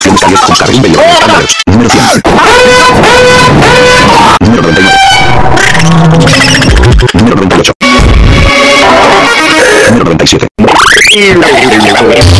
Goofy Ahh Sounds!Bass!